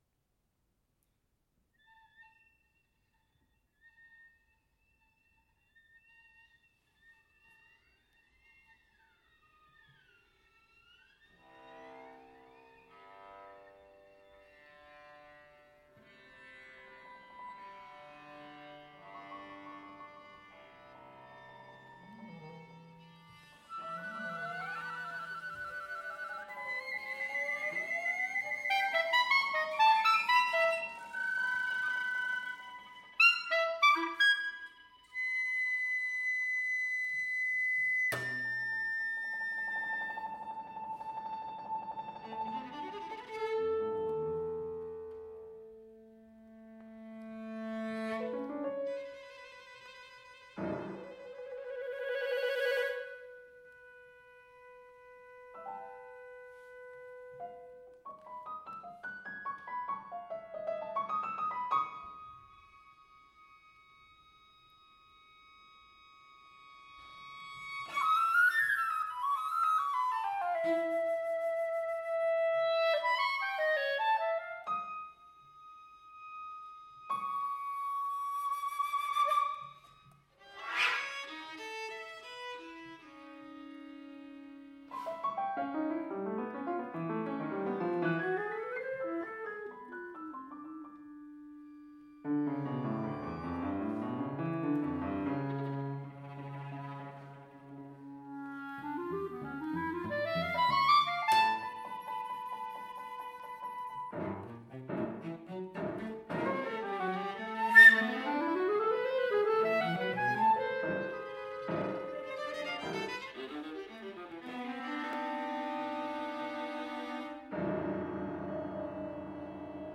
Pierrot ensemble (flute/piccolo, clarinet/bass clarinet, viola, cello, and piano)